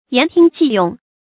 言聽計用 注音： ㄧㄢˊ ㄊㄧㄥ ㄐㄧˋ ㄩㄥˋ 讀音讀法： 意思解釋： 同「言聽計從」。